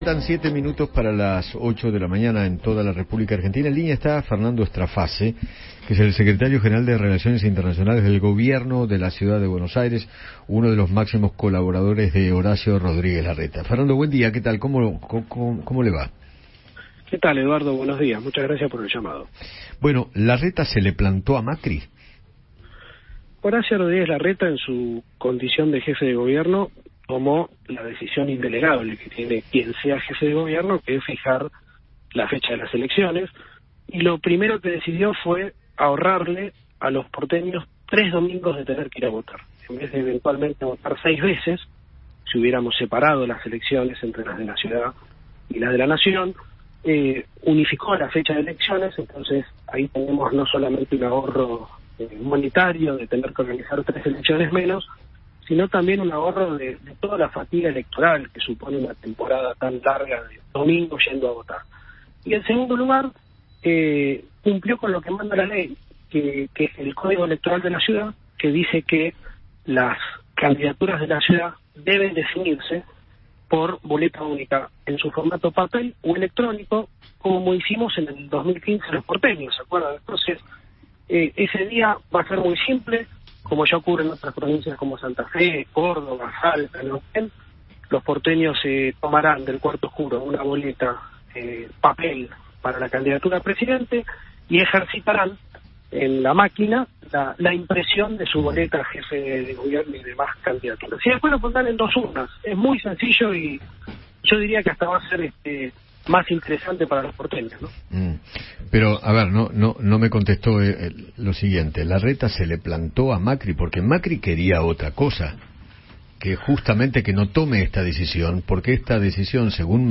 Fernando Straface, secretario General y Relaciones Internacionales del GCBA, dialogó con Eduardo Feinmann sobre la decisión del jefe de Gobierno porteño de desdoblar las elecciones de CABA.